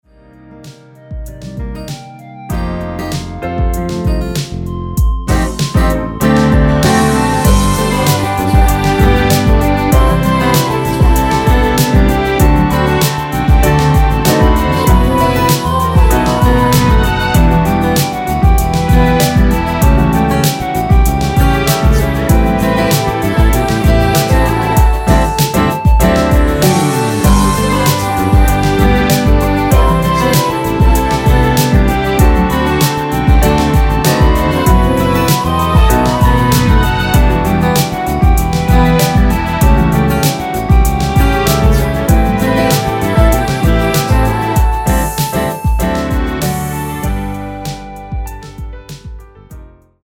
원키 멜로디와 코러스 포함된 MR입니다.(미리듣기 확인)
Bb
앞부분30초, 뒷부분30초씩 편집해서 올려 드리고 있습니다.
중간에 음이 끈어지고 다시 나오는 이유는